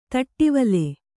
♪ taṭṭivale